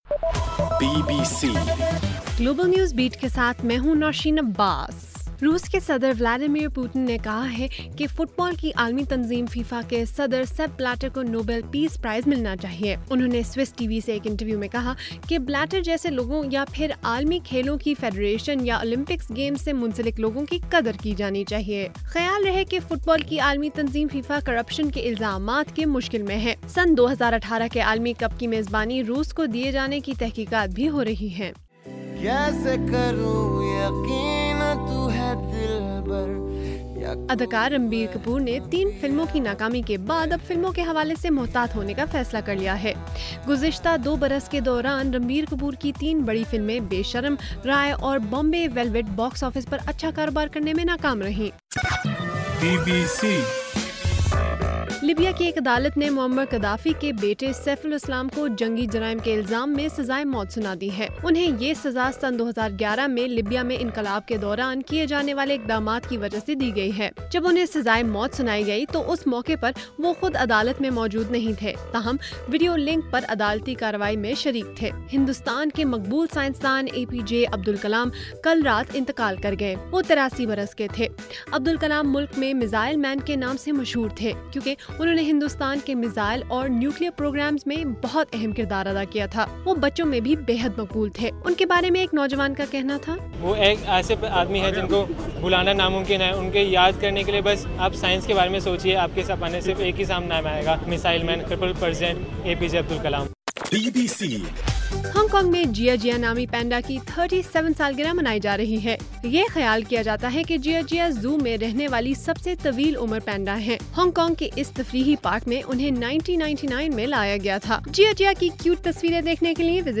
جولائی 28: رات 9 بجے کا گلوبل نیوز بیٹ بُلیٹن